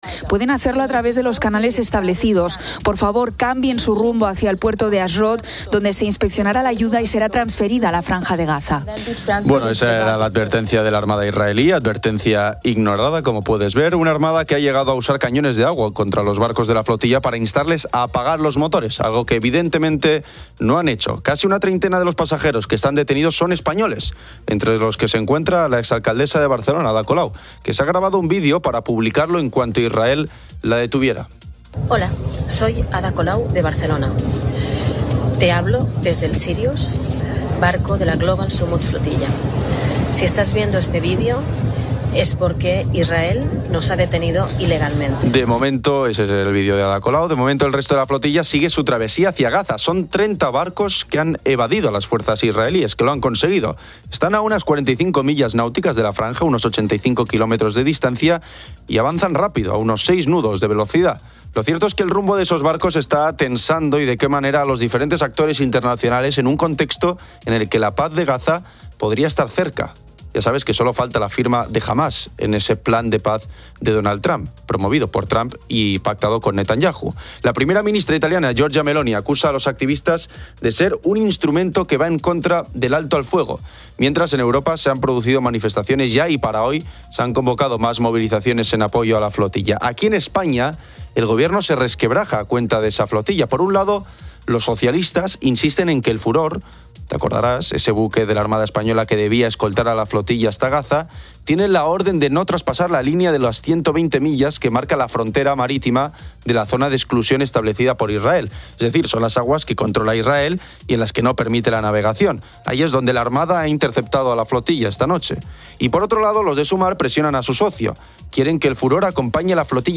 El mercado laboral español finaliza el verano con casi 200.000 empleos menos en agosto y se espera un repunte del paro en septiembre. Oyentes de COPE comparten mensajes sobre sus mascotas.